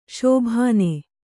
♪ śobhāne